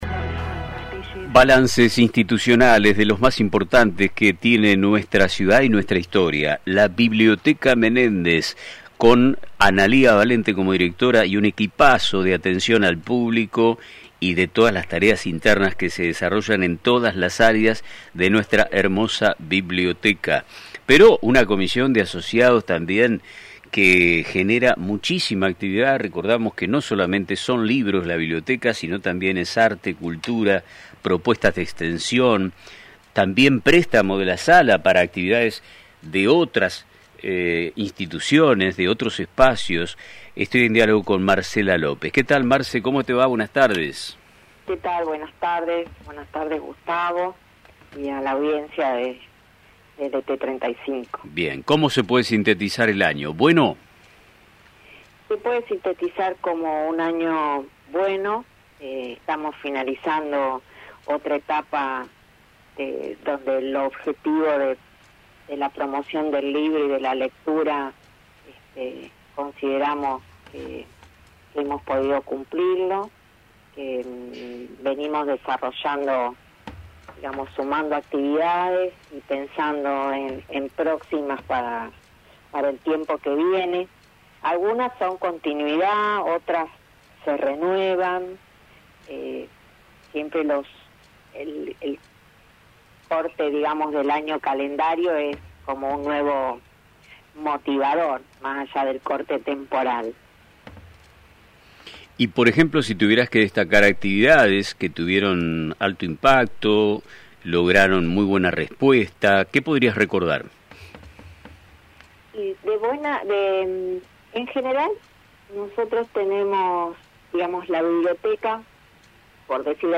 En una entrevista concedida al programa Nuestro Tiempo